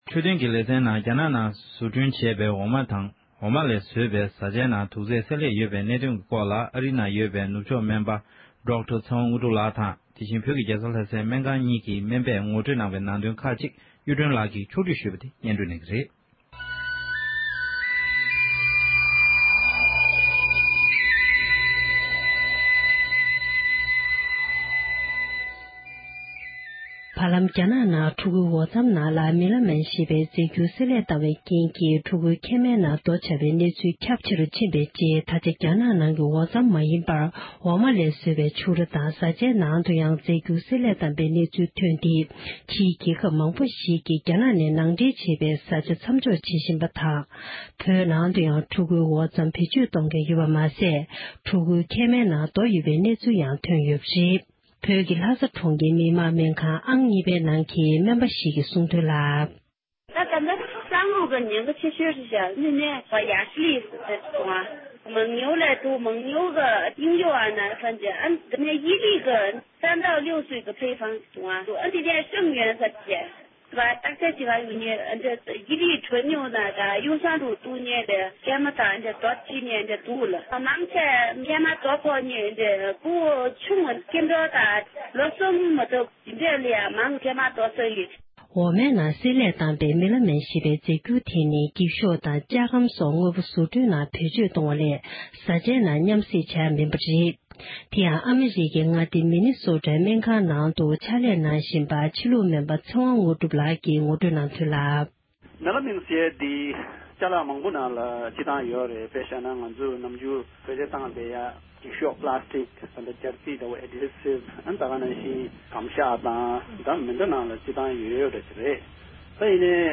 ཨ་རི་དང་བོད་ནང་གི་སྨན་པ་ཁག་ཅིག་ལ་བཅའ་འདྲི་ཞུས་པའི་གནས་ཚུལ།